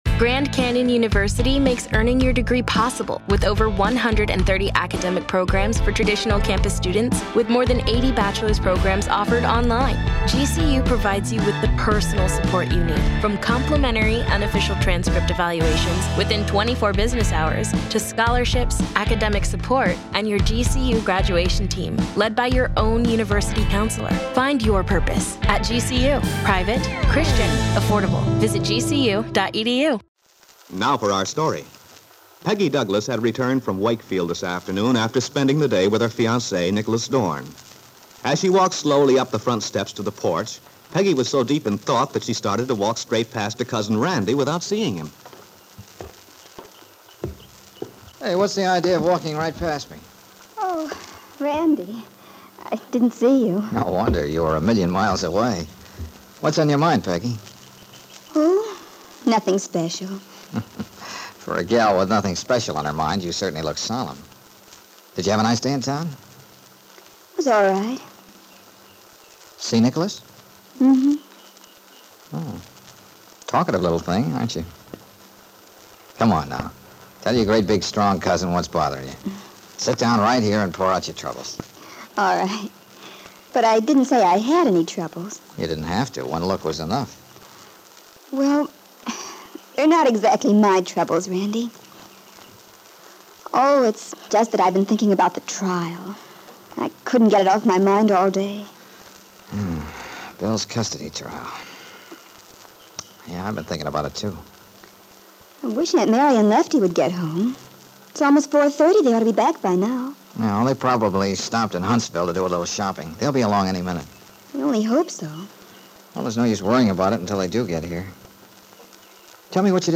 GOLDEN CLASSIC RADIO SHOWS
Aunt Mary was a soap opera that follows a story line which appears to have been broadcast in 1945 and early 1946. The plot features a love triangle and involves a young woman in a failing marriage who pays an extended visit to "friends" in Los Angeles to conceal a dark secret from her father and others back in Wakefield.